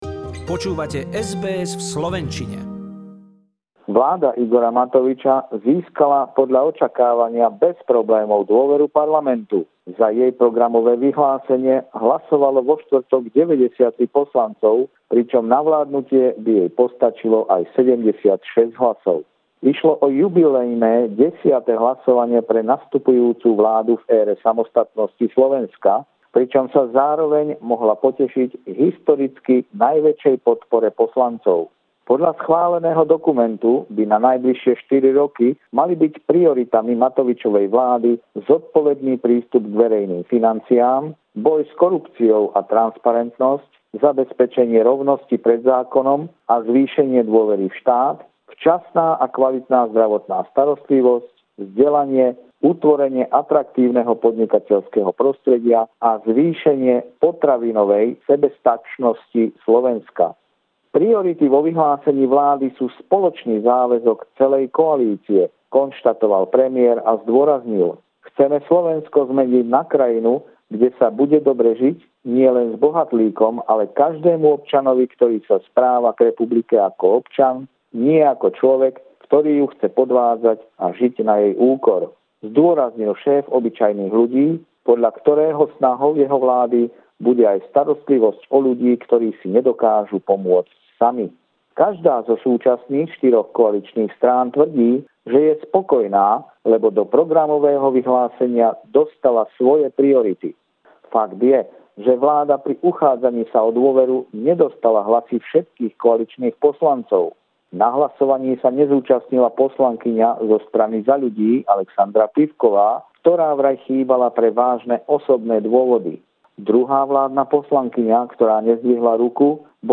Regular stinger report